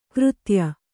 ♪ křtya